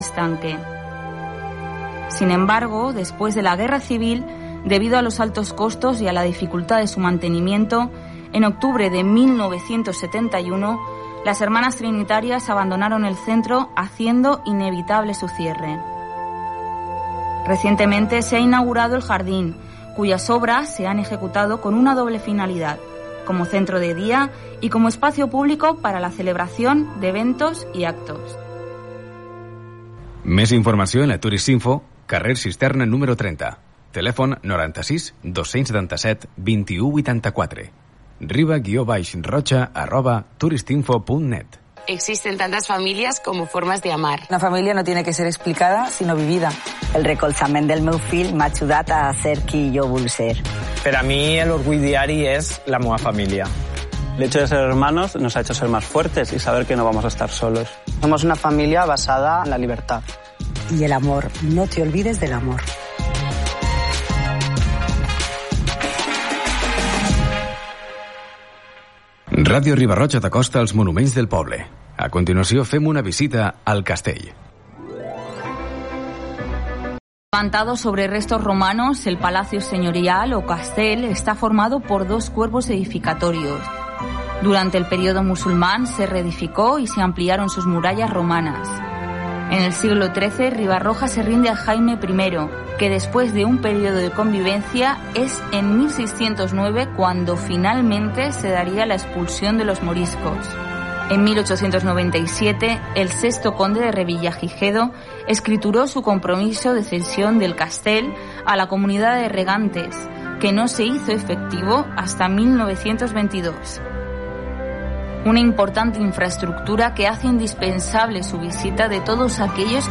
en el que se da cabida a la mejor música de jazz del recuerdo, jazz clásico, contemporáneo y de actualidad, junto con entrevistas y últimos conciertos.